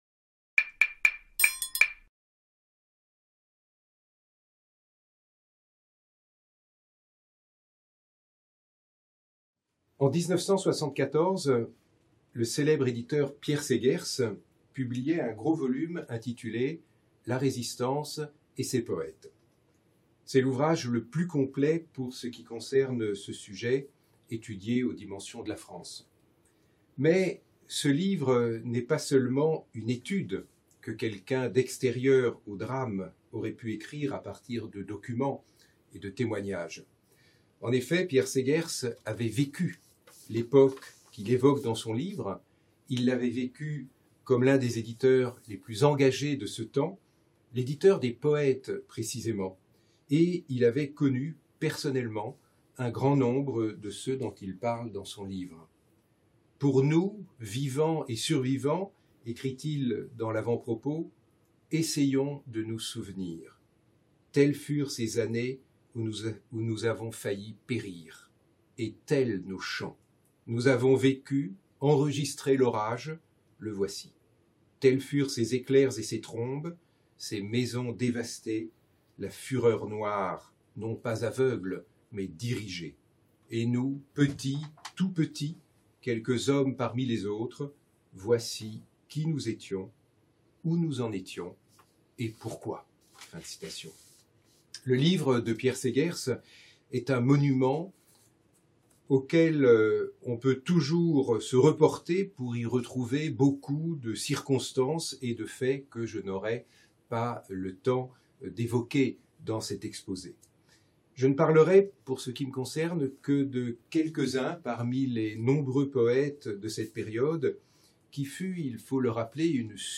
L'intervenant donne lecture d'extraits des poèmes suivants :